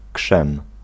Ääntäminen
IPA : /ˈsɪlɪkən/